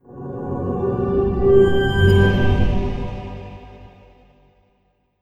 OS2 Warp 10.0 Shutdown.wav